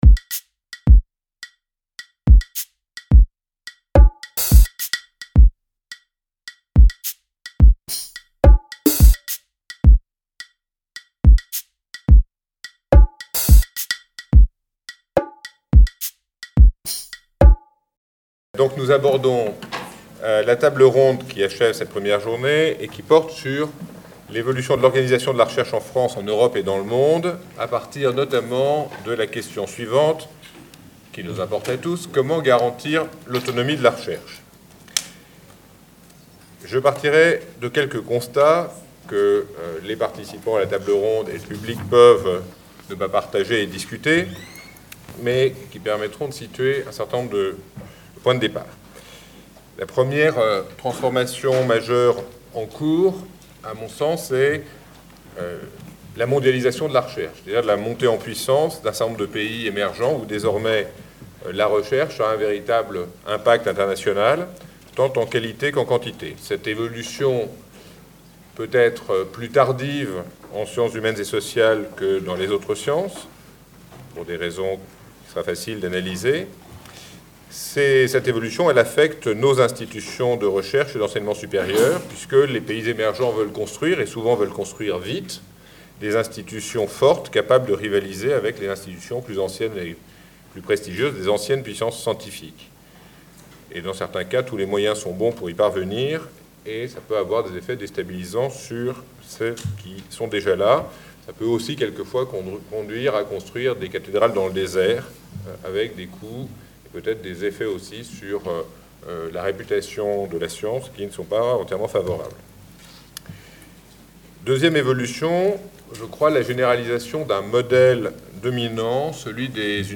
Table ronde : L’évolution de l’organisation de la recherche en France, en Europe et dans le monde : comment garantir l’autonomie de la recherche ?